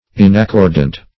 Inaccordant \In`ac*cord"ant\, a.